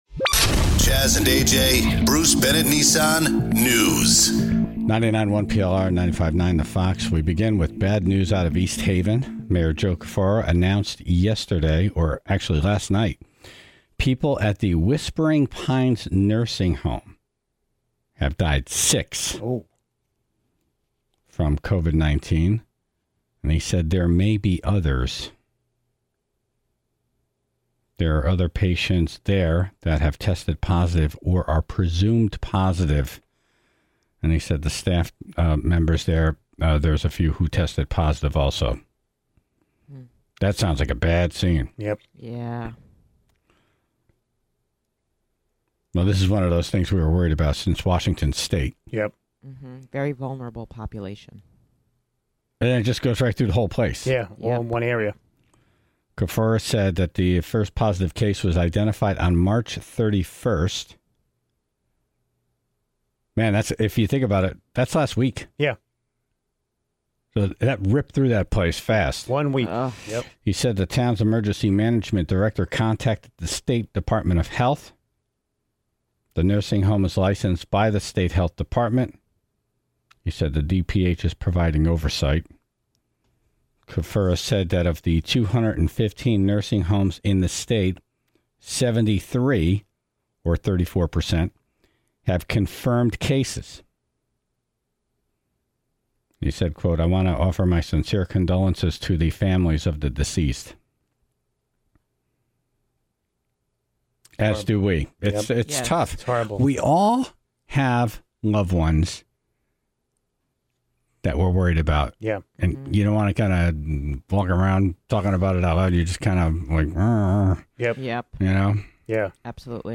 (39:41) Mayor Carfora of East Haven called in to address the horrible news of multiple deaths relating to COVID-19 at a nursing home.